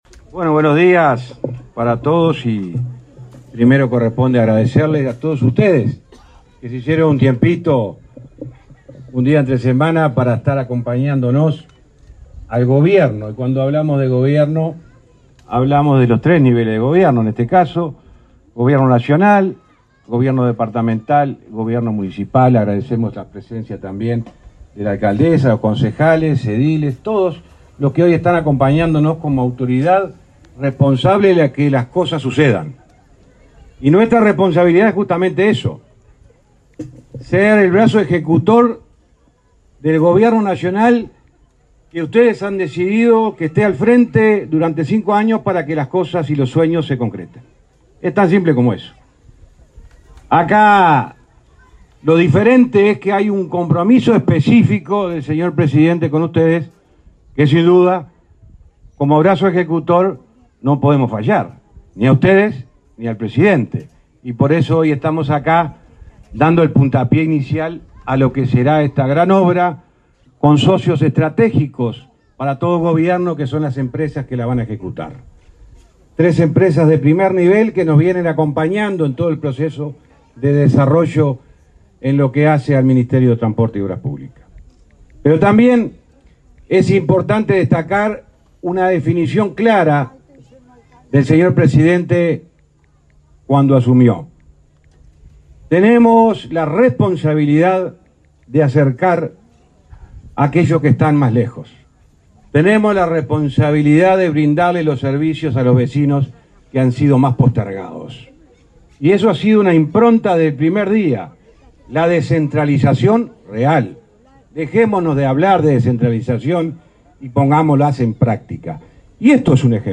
Lanzamiento de obras de construcción del puente sobre el río Cebollatí 16/02/2022 Compartir Facebook X Copiar enlace WhatsApp LinkedIn Este miércoles 16, el ministro de Transporte, José Luis Falero, y los intendentes de Treinta y Tres y Rocha, Mario Silvera y Alejo Umpiérrez, participaron del acto de lanzamiento de las obras del puente que une La Charqueada con Cebollatí.